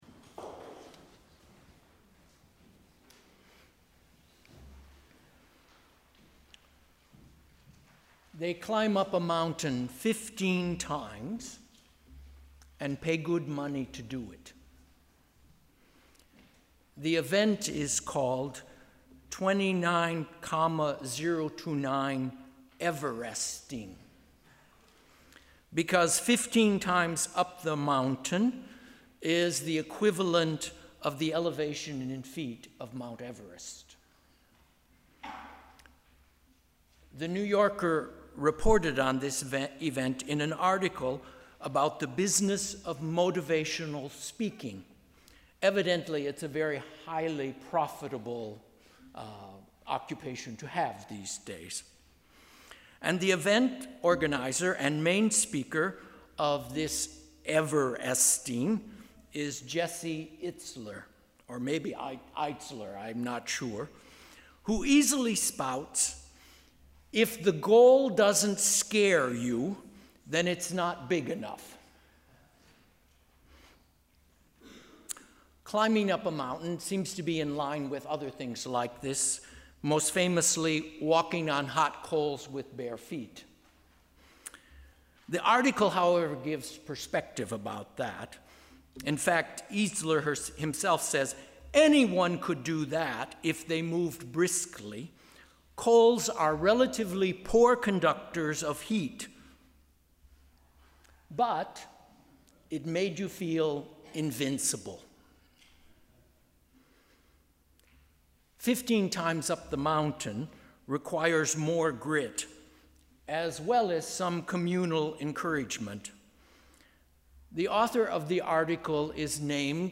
Sermon: ‘God in your arms’